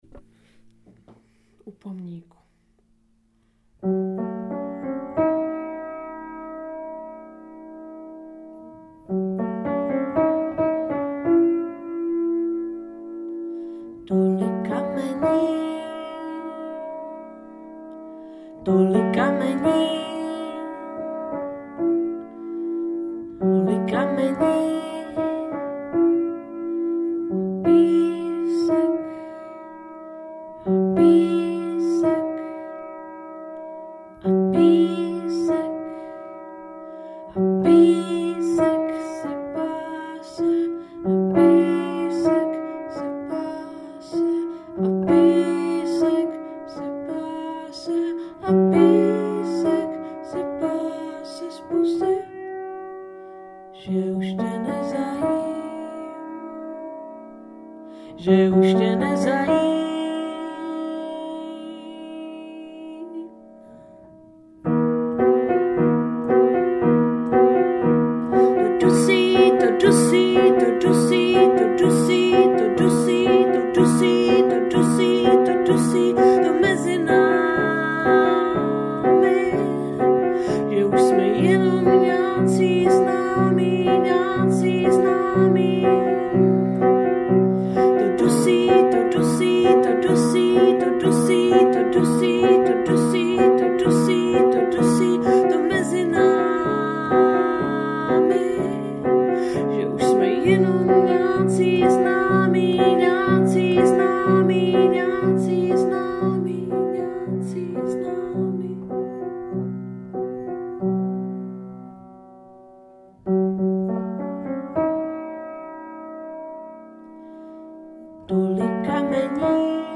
STE-118_kameni_klavir.mp3